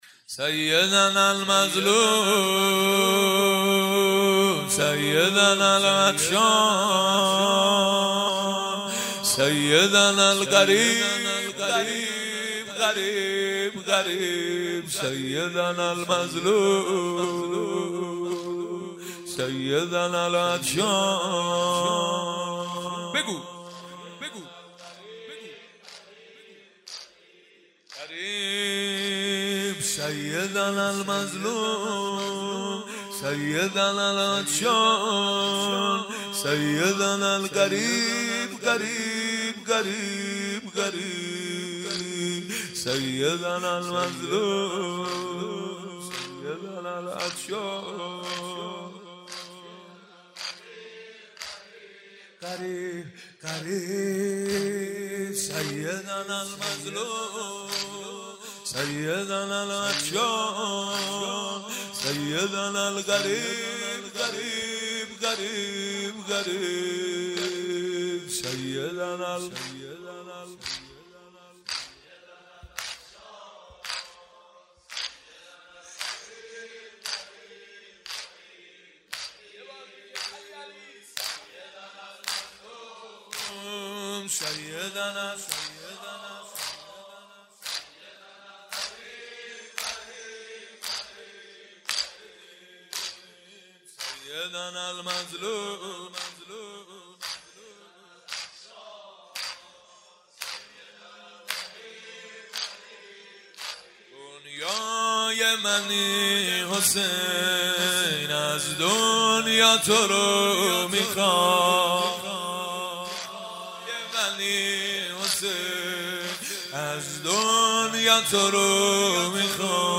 سخنرانی: زیاده گویی؛ عامل ضلالت و گمراهی